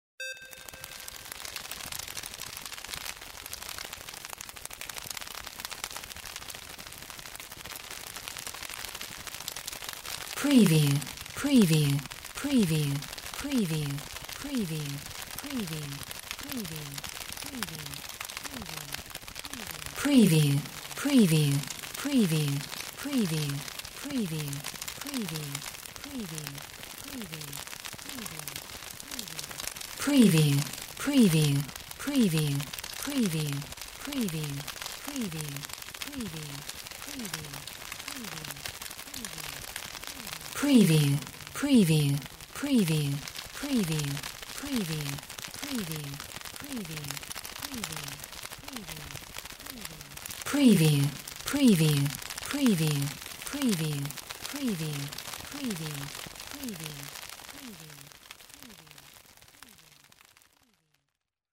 Bats wings 01
Bats wings flapping
Stereo sound effect - Wav.16 bit/44.1 KHz and Mp3 128 Kbps
PREVIEW_ANM_BATS_WINGS_WBHD01.mp3